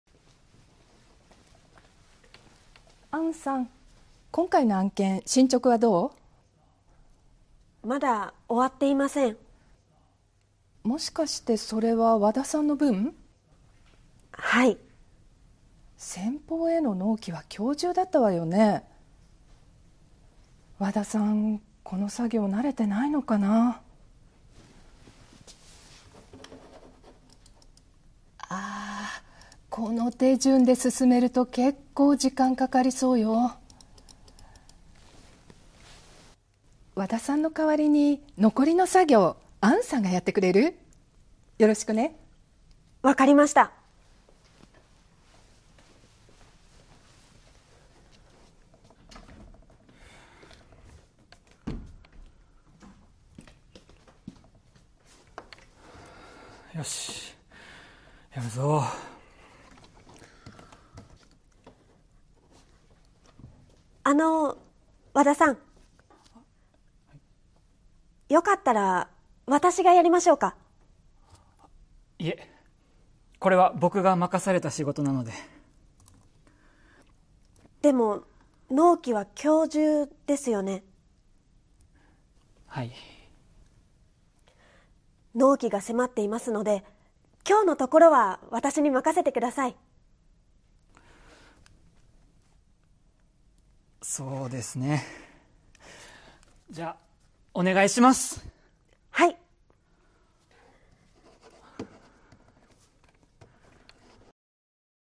Role-play Setup
skit38.mp3